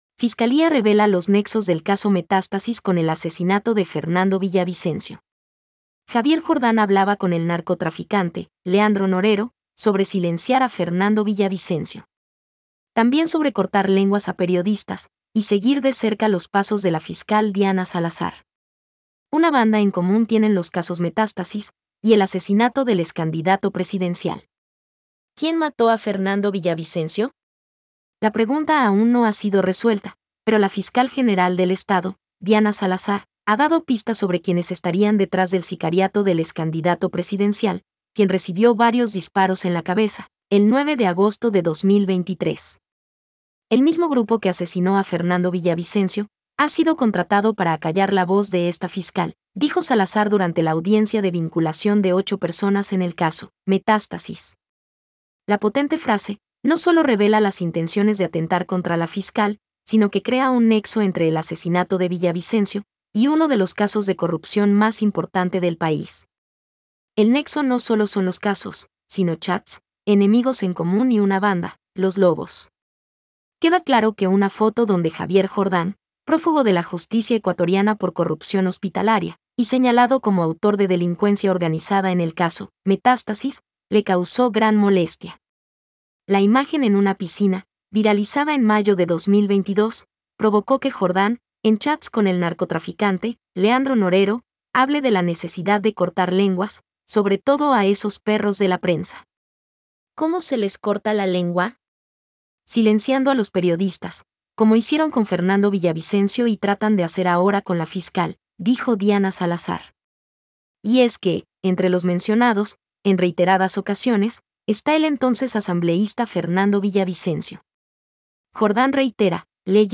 Noticia hablada